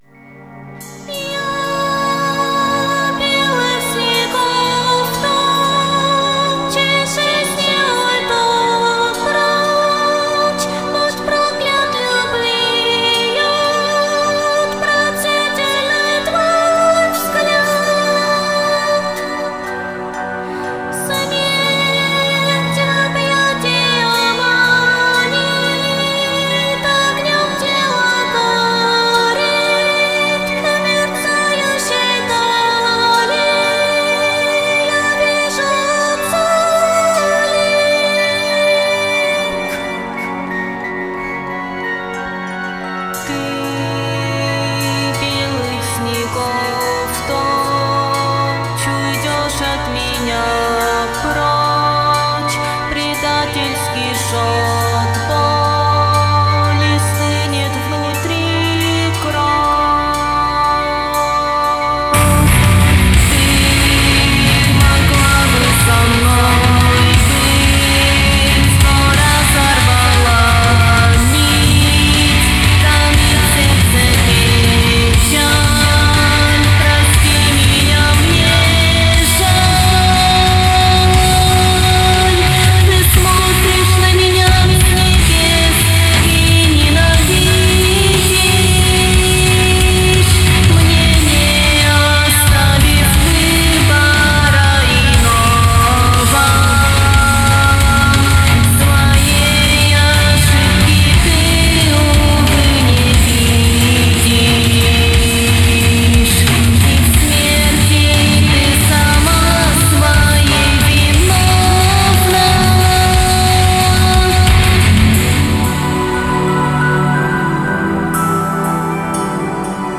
Вложения Фэнтези-нойз-опера-голос отчаяния и одиночества в мире боли и жестокости.mp3 Фэнтези-нойз-опера-голос отчаяния и одиночества в мире боли и жестокости.mp3 10,8 MB · Просмотры: 3.197